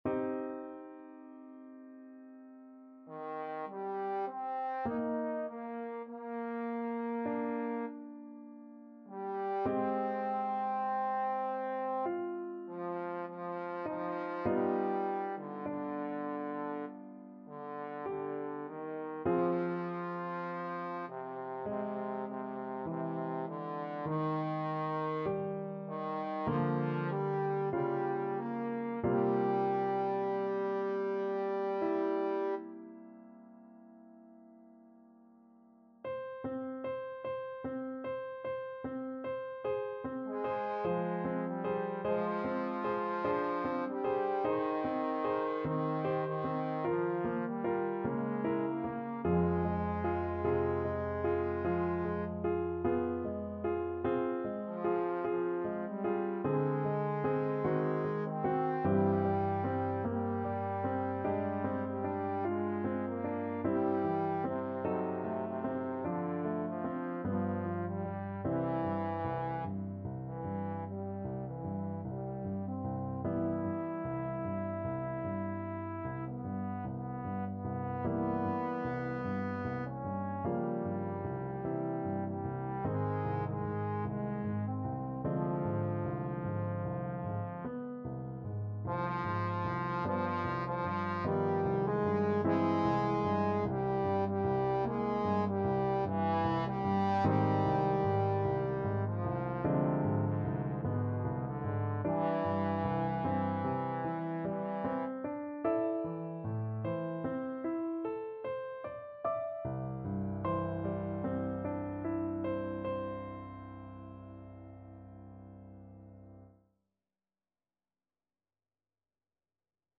Classical Rachmaninoff, Sergei 12 Romances Op 21, No. 3 Twilight Trombone version
Trombone
C minor (Sounding Pitch) (View more C minor Music for Trombone )
4/4 (View more 4/4 Music)
~ = 100 Lento =50
Classical (View more Classical Trombone Music)